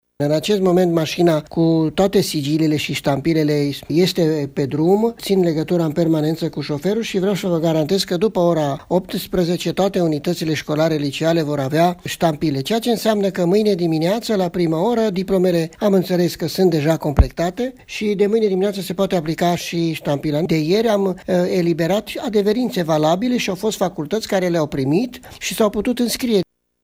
Astăzi, după ora 18 ștampilele vor ajunge la Inspectoratul Școlar Județean Mureș a declarat pentru Radio Tg.Mureş Ioan Macarie, inspector școlar general al Inspectoratului Școlar Județean Mureș: